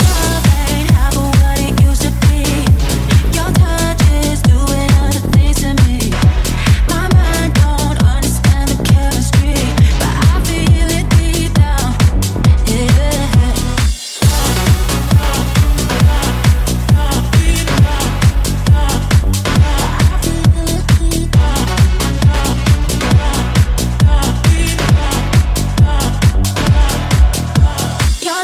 Genere: pop, slap, deep, house, edm, remix